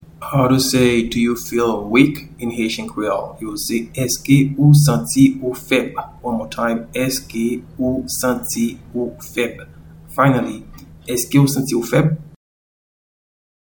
Pronunciation and Transcript:
Do-you-feel-weak-in-Haitian-Creole-Eske-ou-santi-ou-feb.mp3